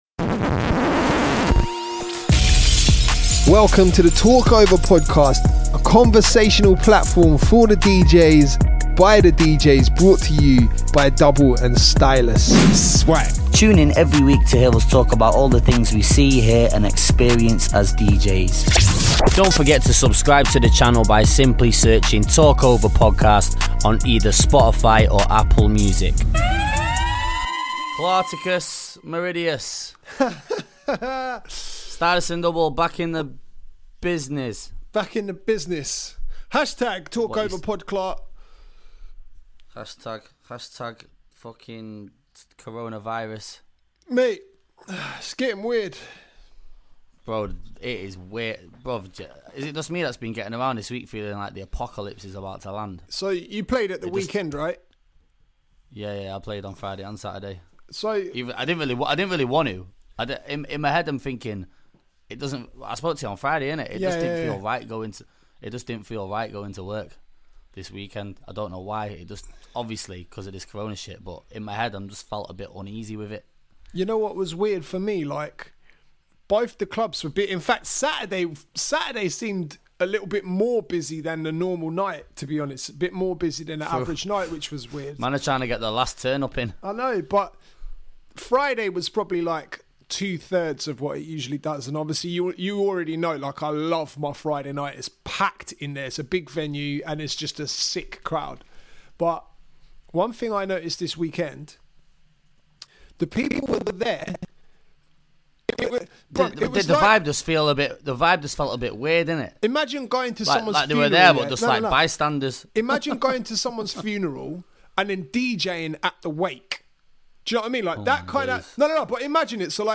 We discuss relevant topics, giving our unfiltered & honest opinions about anything that comes up in conversation!